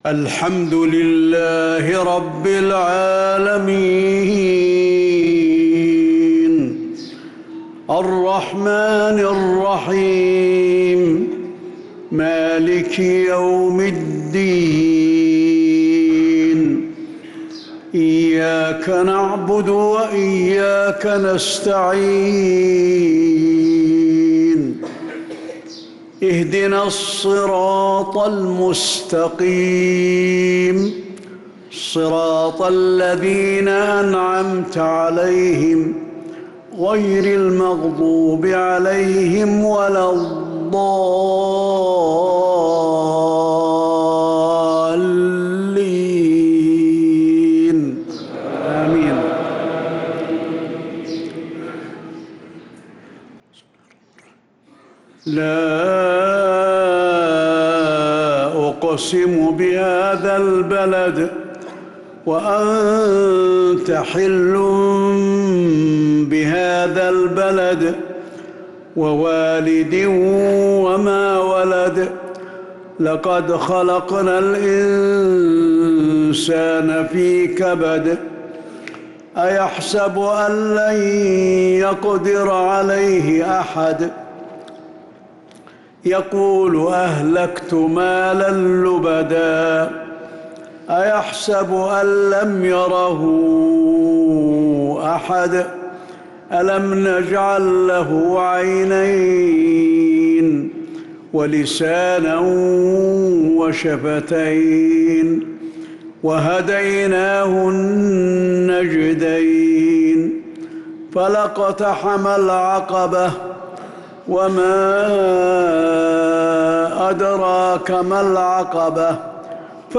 مغرب الأبعاء 5-9-1446هـ سورتي البلد و القدر كاملة | Maghrib prayer Surat al-Balad & al-qadr 5-3-2025 > 1446 🕌 > الفروض - تلاوات الحرمين